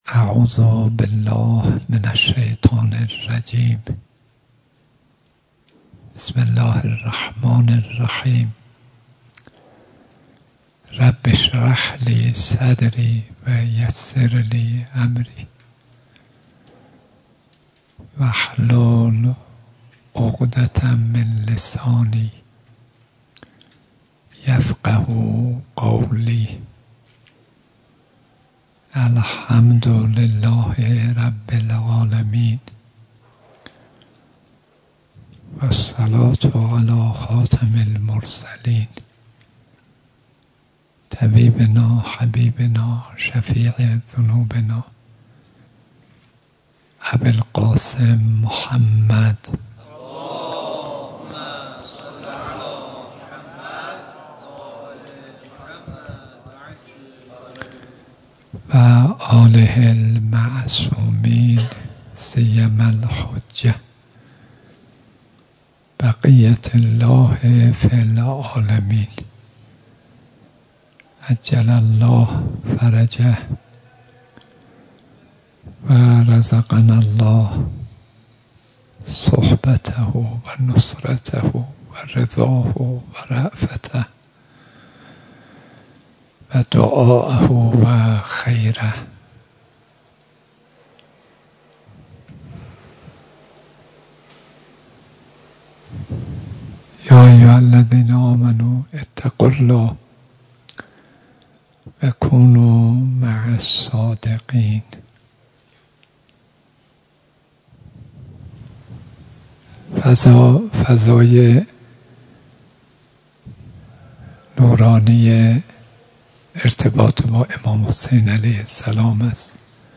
درس اخلاق آیت الله صدیقی برگزار گردید +صوت
ظهر امروز 22 مهر 1396 مراسم درس اخلاق حضرت آیت الله صدیقی در مسجد حوزه علمیه امام خمینی(ره) تهران با حضور معاونت ها، اساتید و طلاب معزز برگزار گردید.